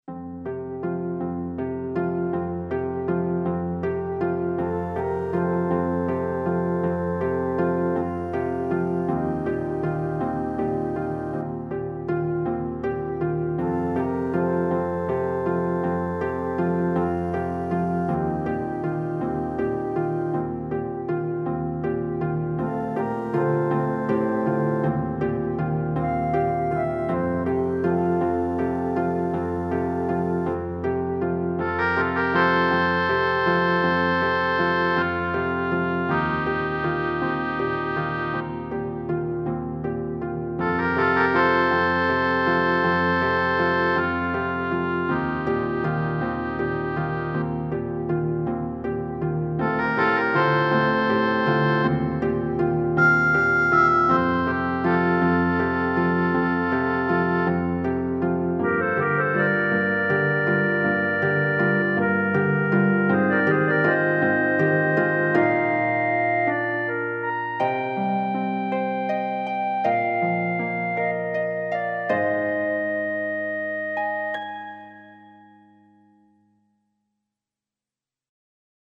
ゆったりとした可愛らしい曲。